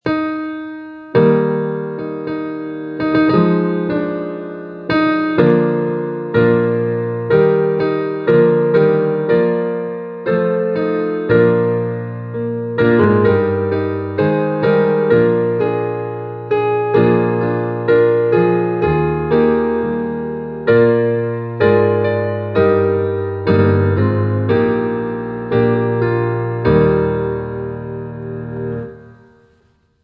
Gloria-Patri-Piano.mp3